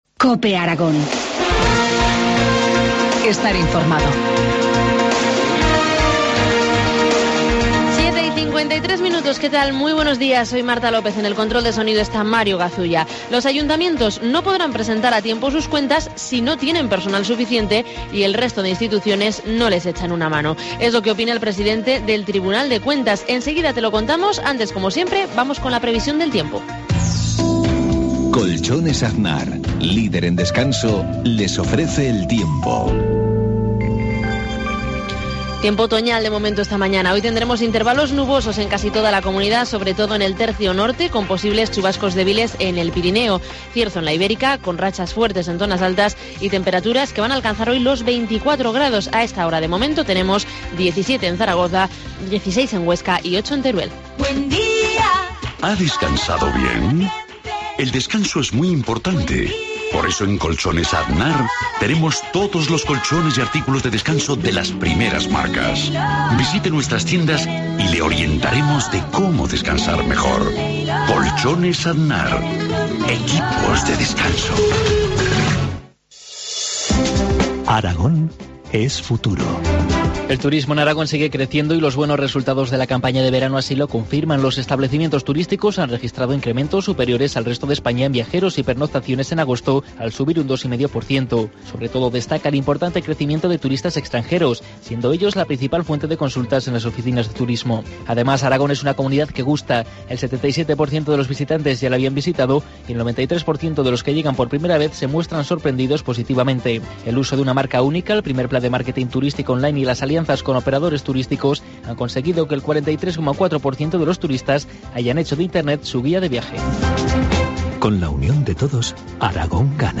Informativo matinal, martes 15 de octubre, 7.53 horas